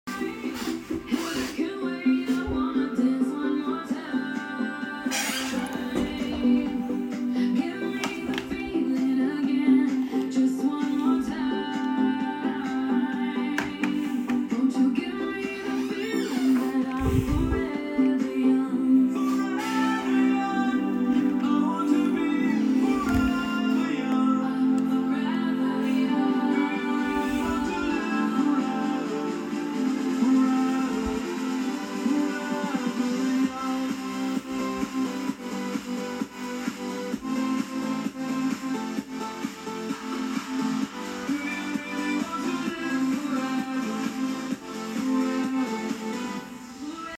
CD Wiedergabe auf PS3 Slim sound effects free download